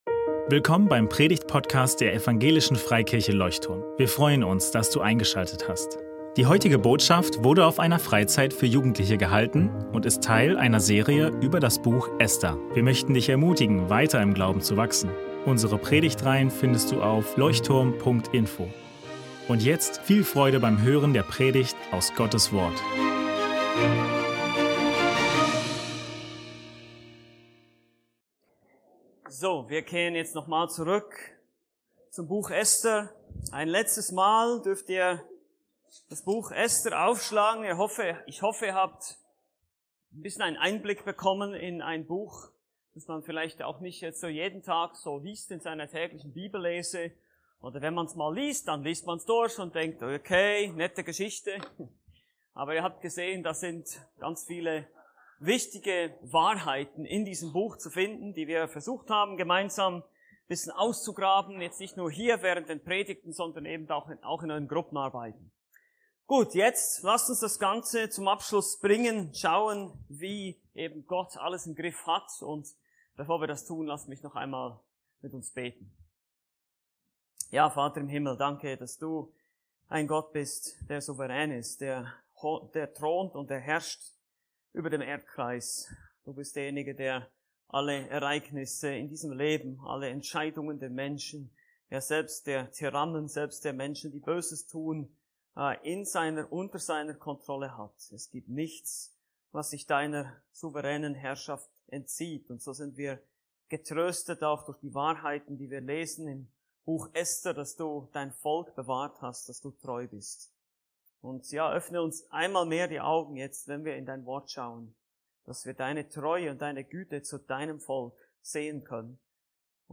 Gott bewahrt sein Volk ~ Leuchtturm Predigtpodcast Podcast